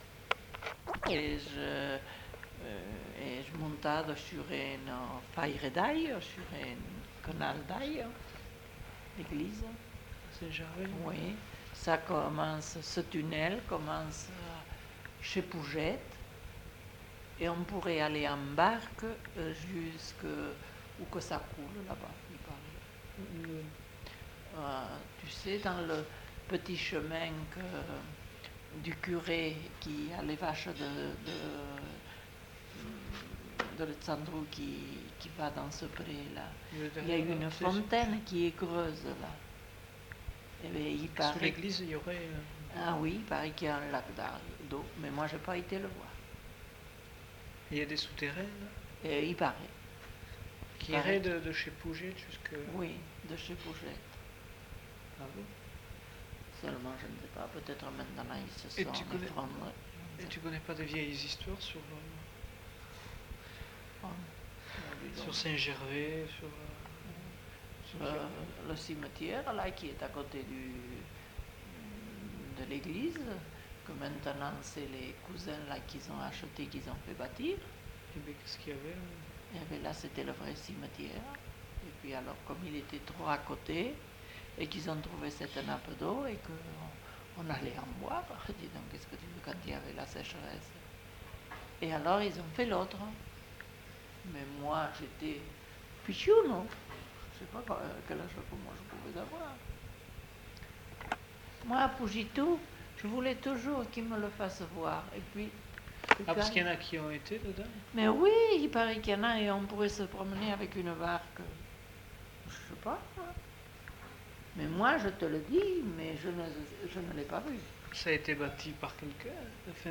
Lieu : Saint-Amans-des-Cots
Genre : conte-légende-récit
Type de voix : voix de femme
Production du son : parlé
Classification : récit légendaire